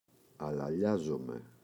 αλαλιάζομαι [alaꞋʎazome]